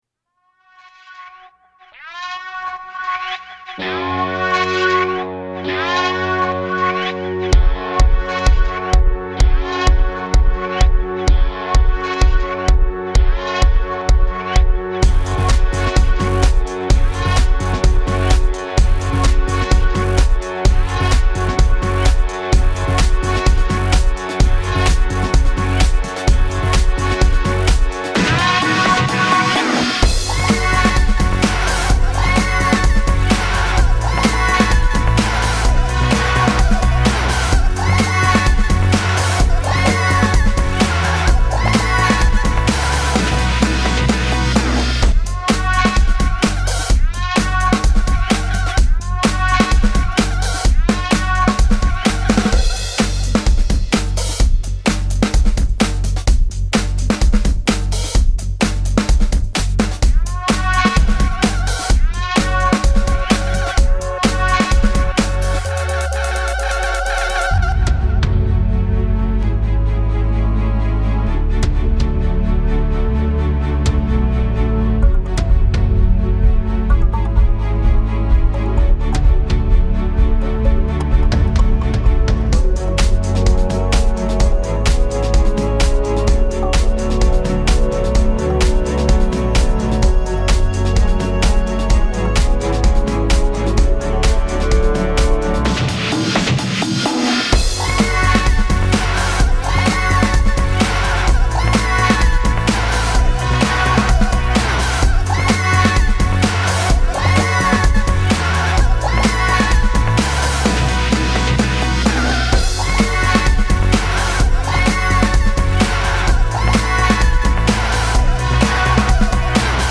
Playlist – Rock